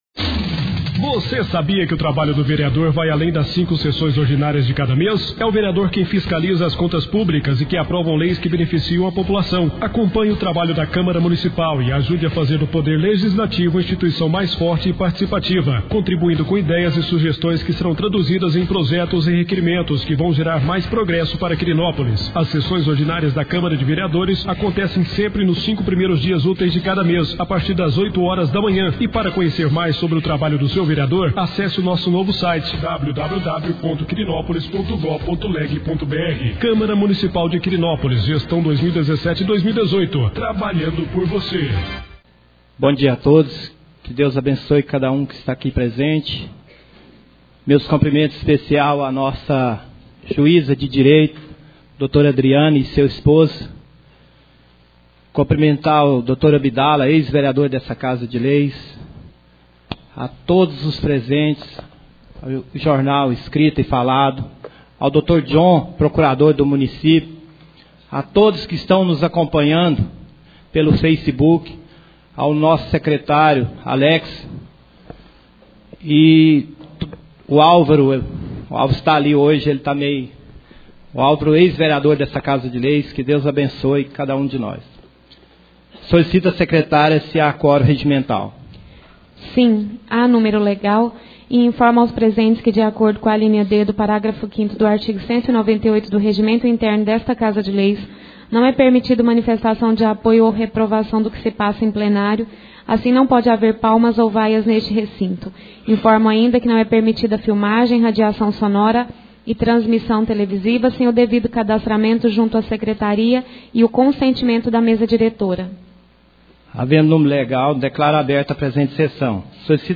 4° Sessão Ordinária do Mês de Junho 2017.